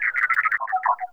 turret_2.wav